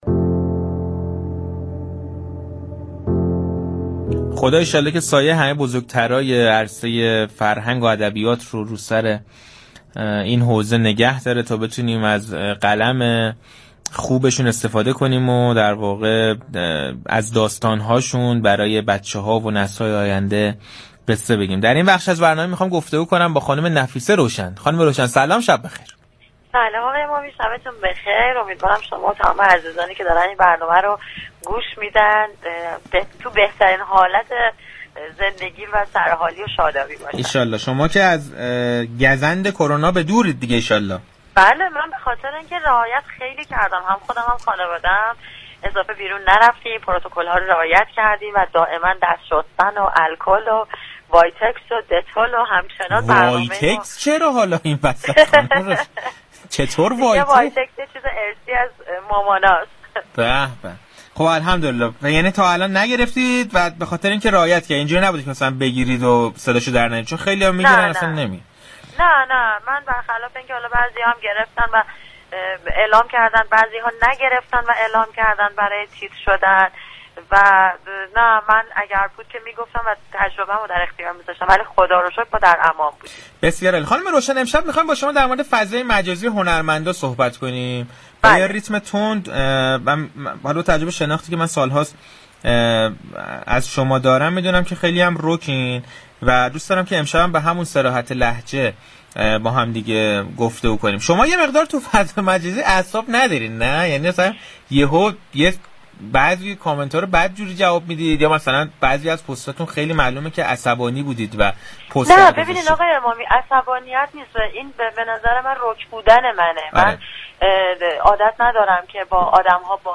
نفیسه روشن در گفتگوی تلفنی با برنامه پشت صحنه رادیو تهران گفت: صفحات غیر واقعی در اینستاگرام با هدف تخریب هنرمندان ایجاد می شوند و برخی هم مطالب آنها را باور می‌كنند.